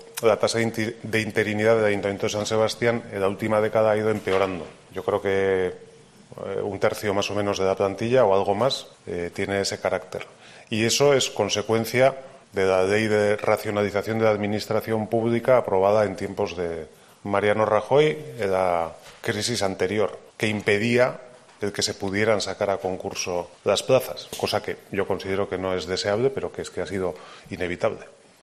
Eneko Goia, alcalde de San Sebastián